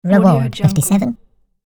دانلود افکت صوتی صدای کارتونی جایزه 57
Cartoon Voice Says Reward 57 royalty free audio track is a great option for any project that requires cartoon sounds and other aspects such as a cartoon, fun and infographic.
Sample rate 16-Bit Stereo, 44.1 kHz
Looped No